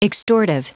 Prononciation du mot extortive en anglais (fichier audio)
Prononciation du mot : extortive